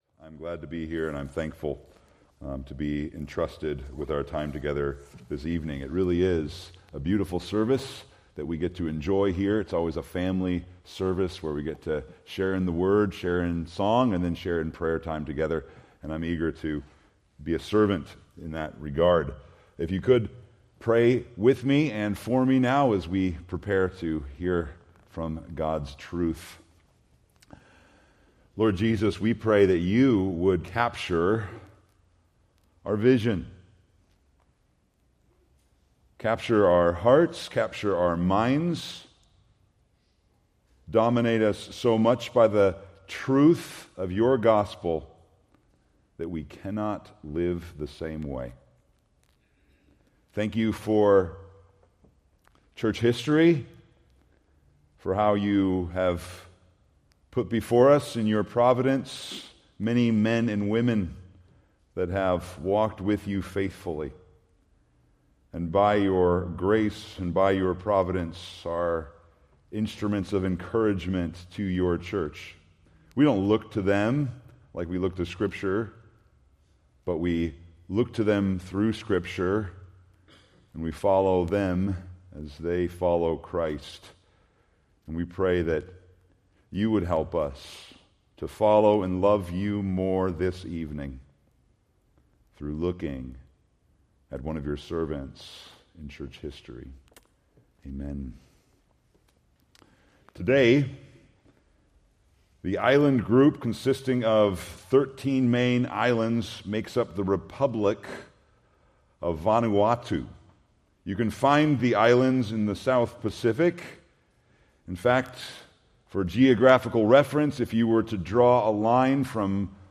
Preached January 18, 2026 from Selected Scriptures